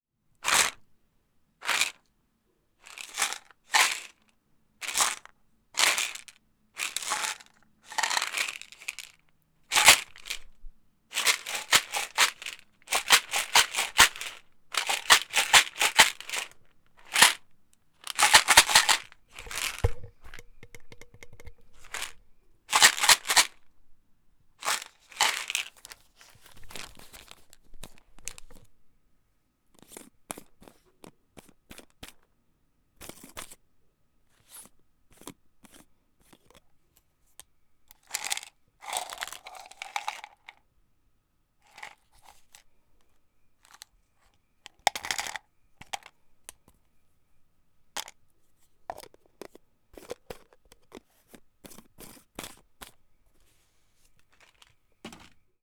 pill-bottle.wav